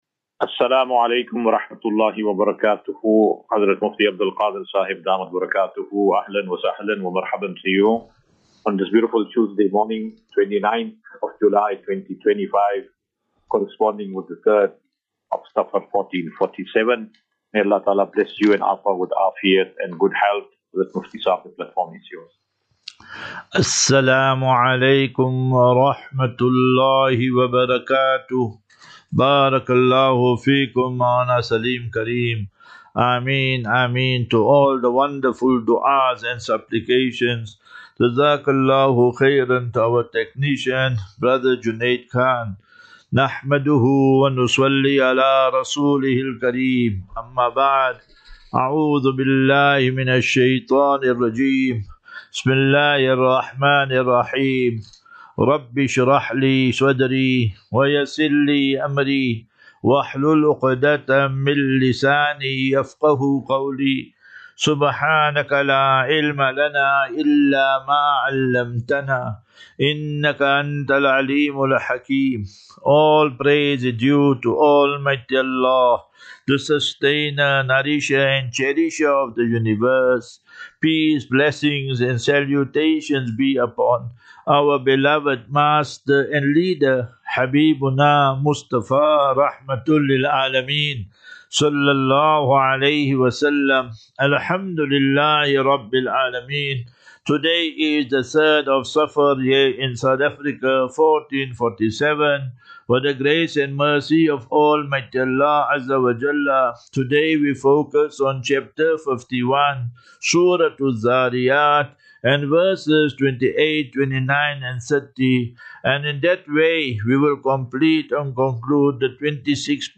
QnA.